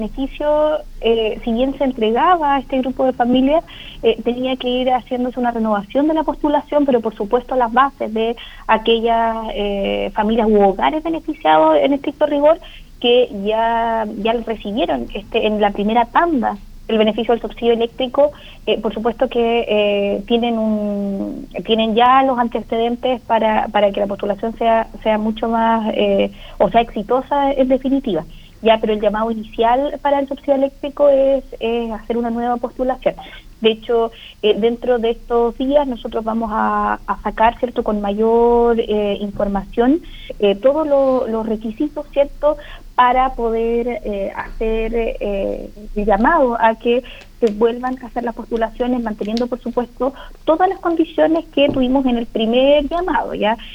Los clientes que ya fueron beneficiados y no cambiaron sus condiciones hasta el 1 de octubre, tendrán preferencias para recibir el subsidio de manera automática durante el primer semestre de 2025, como explicó la Seremi de Gobierno en Los Lagos, Danitza Ortiz.